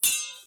swordsClashing1.ogg